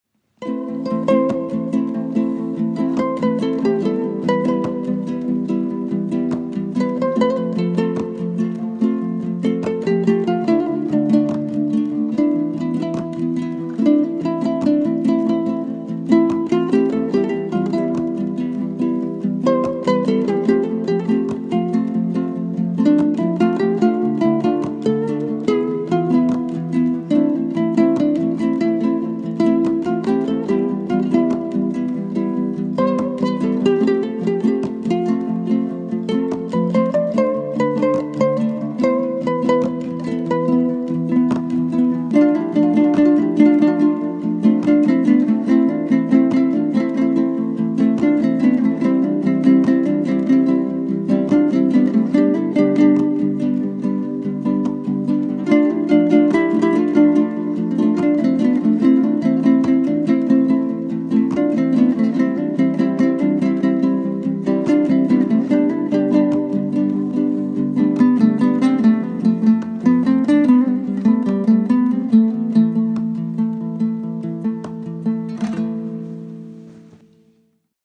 cover әні домбырамен